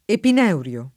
epinervio [ epin $ rv L o ] s. m. (med.); pl. ‑vi (raro, alla lat., -vii ) — anche epineurio [ epin $ ur L o ] (pl. -ri ; raro, alla lat., -rii ) o epinevrio [ epin $ vr L o ] (pl. -vri ; raro, alla lat., -vrii )